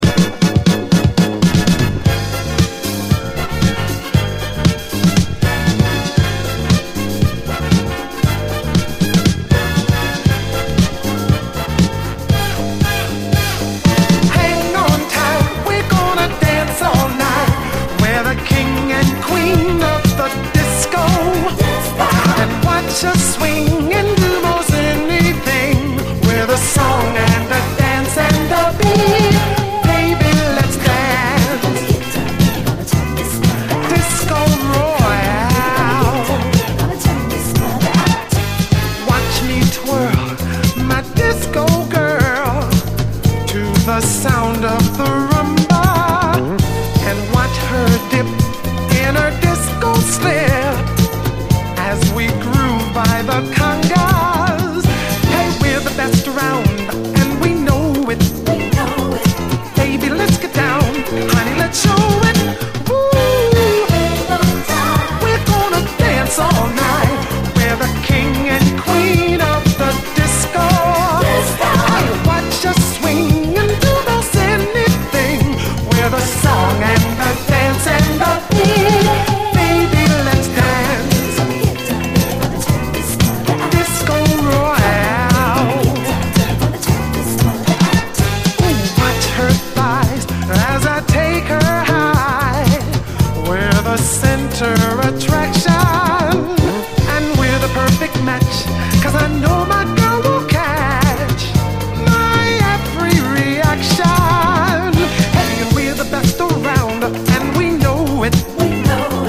フランス産プリAOR〜ブルーアイド・ソウル！甘く軽やかなコーラス・ハーモニーが優しく包む、美メロ・トラック満載！
フランス・オリジナル盤！